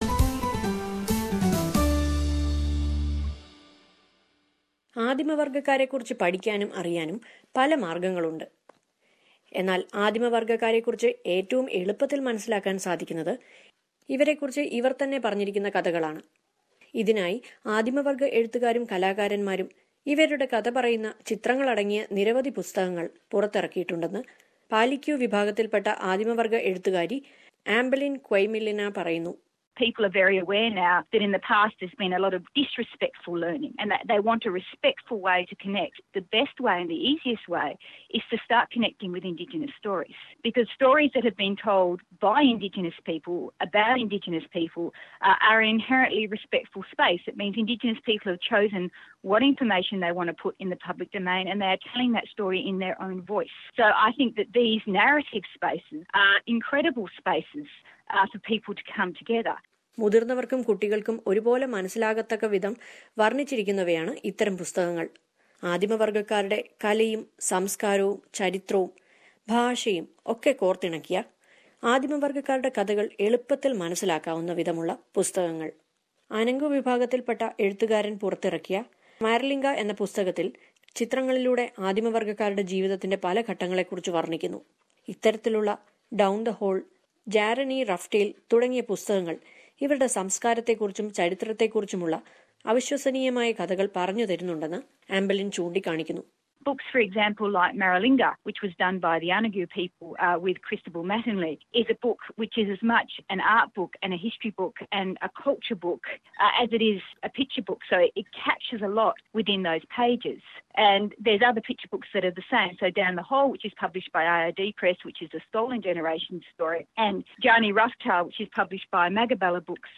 ആദിമവർഗക്കാരെക്കുറിച്ച് എളുപ്പത്തിൽ മനസിലാക്കാനായി ഇവർക്കിടയിലെ എഴുത്തുകാർ ചിത്രങ്ങൾ ഉൾപ്പെടുത്തിക്കൊണ്ടുള്ള പുസ്തകങ്ങൾ പുറത്തിറക്കിയിട്ടുണ്ട്. ഇതേക്കുറിച്ച് ഒരു റിപ്പോർട്ട് കേൾക്കാം മുകളിലെ പ്ലേയറിൽ നിന്ന്...